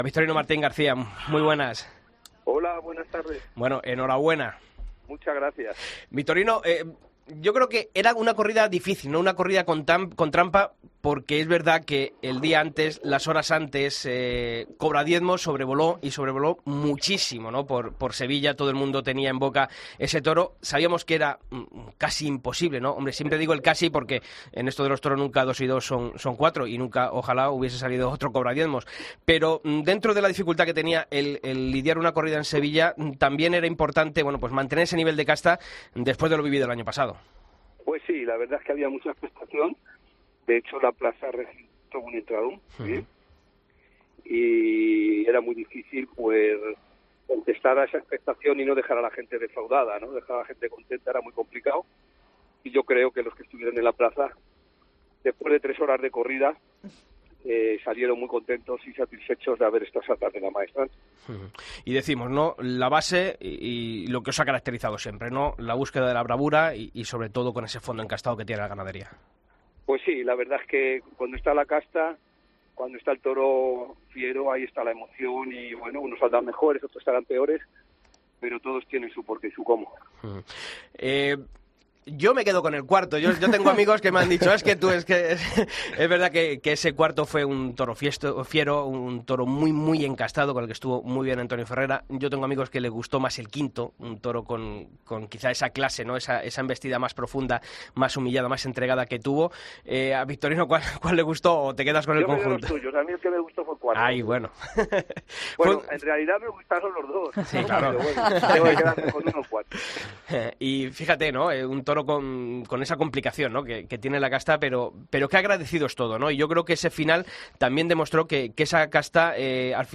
Escucha la entrevista a Victorino Martín en El Albero